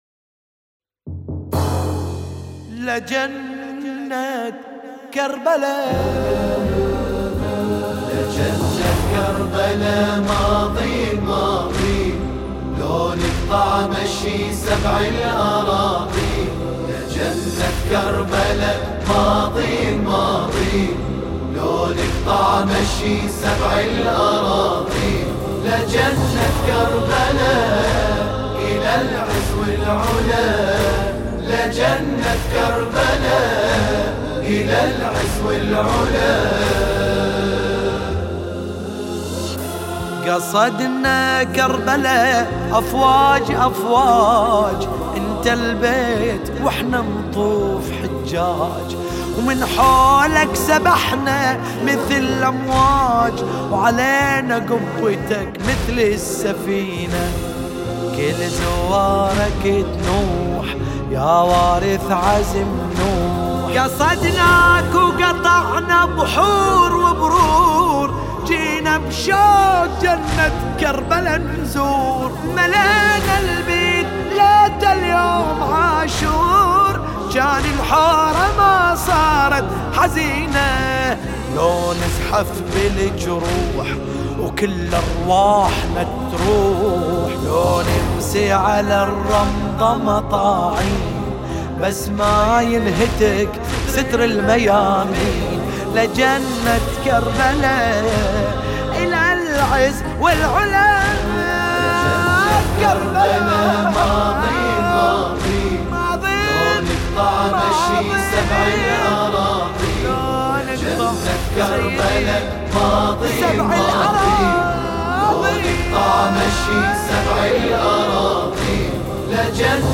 لطميات لطمية